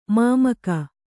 ♪ māmaka